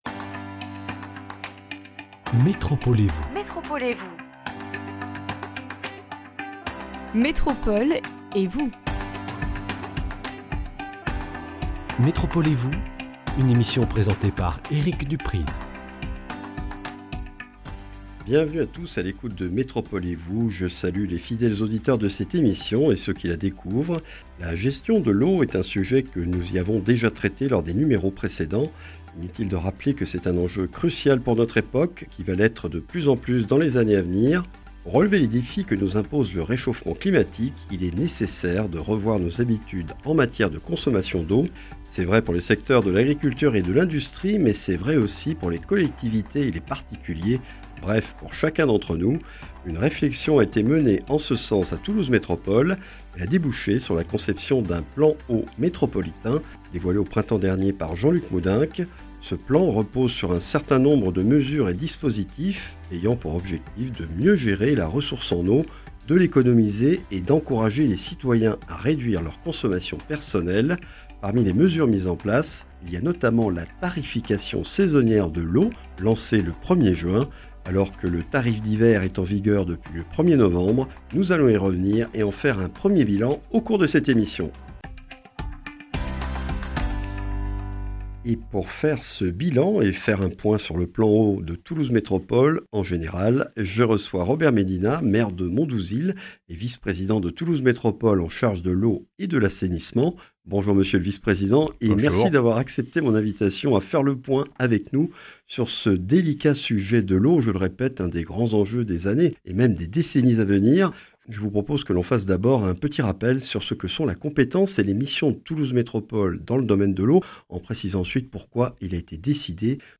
Alors que le tarif d’hiver s’applique depuis le 1er novembre, nous faisons un 1er bilan de la mise en place de la tarification saisonnière de l’eau dans la métropole toulousaine (depuis le 1er juin 2024) avec Robert Médina, maire de Mondouzil, vice-président de Toulouse Métropole en charge de la compétence Eau et Assainissement. Également au menu de ce numéro, un point sur le Plan Eau métropolitain.